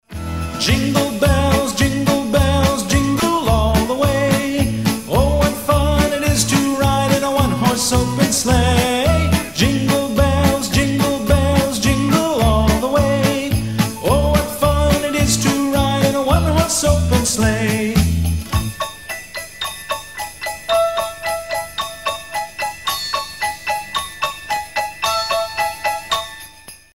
• Качество: 320, Stereo
добрые
колокольчики
рождественские
детские